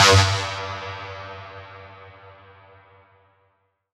Phaze Hit.wav